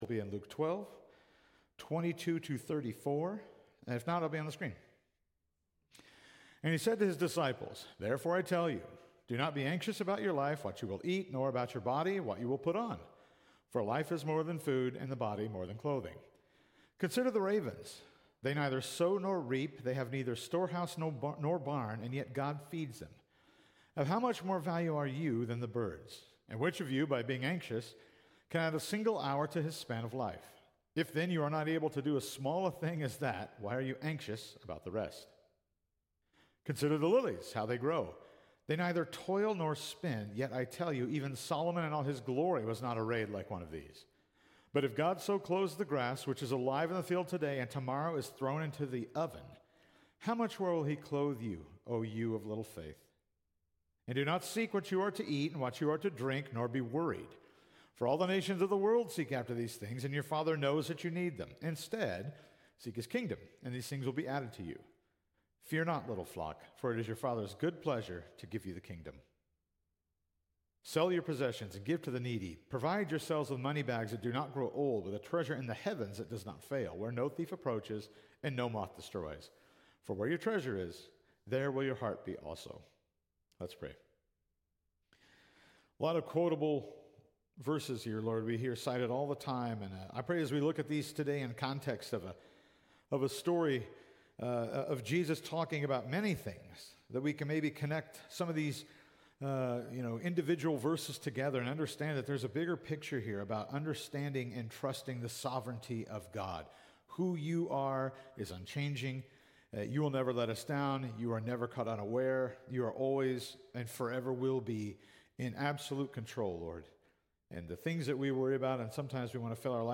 Sermons by Calvary Heights Baptist Church